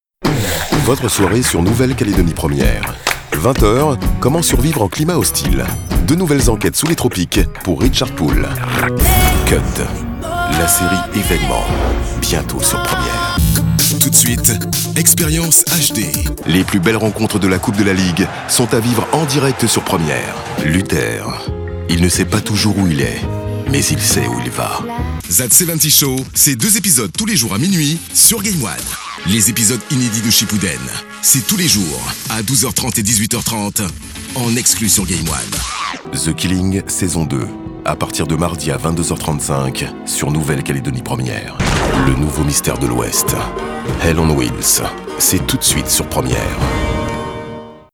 VOIX OFF, animateur radio
Sprechprobe: Industrie (Muttersprache):